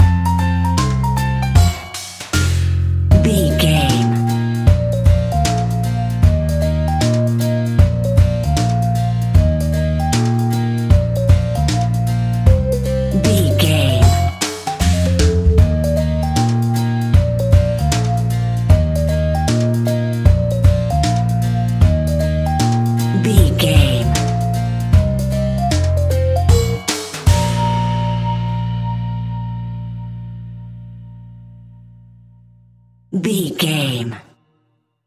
Ionian/Major
B♭
childrens music
childlike
happy
kids piano